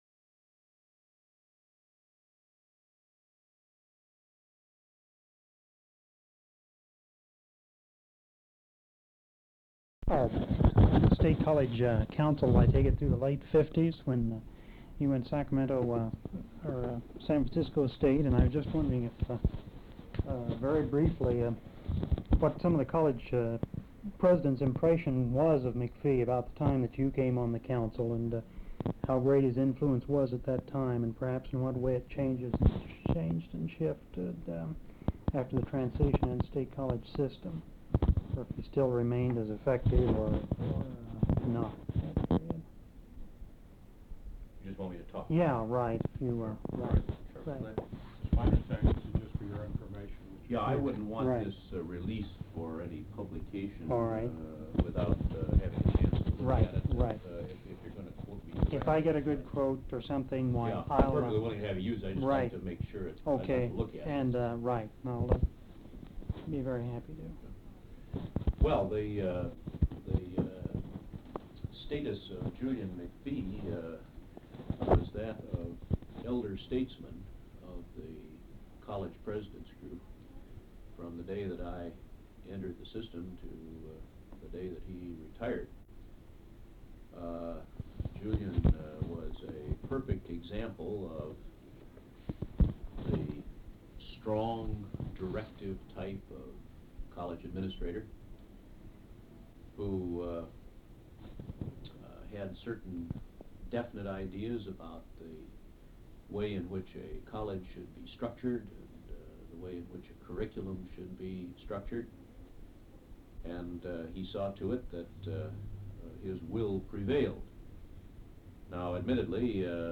Interview with Glenn Dumke
Form of original Open reel audiotape